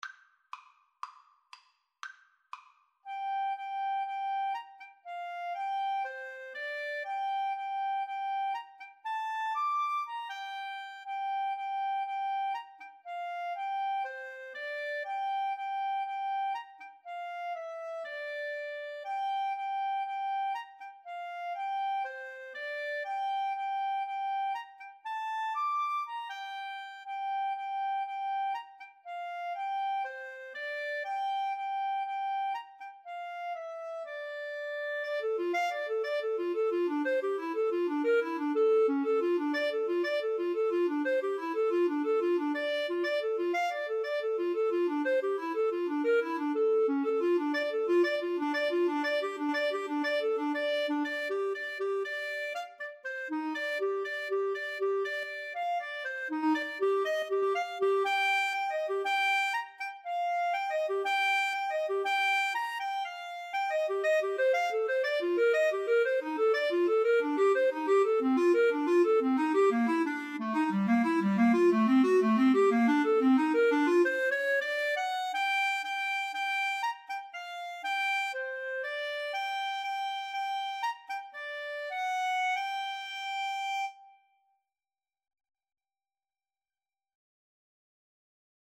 ClarinetAlto Saxophone
Allegro (View more music marked Allegro)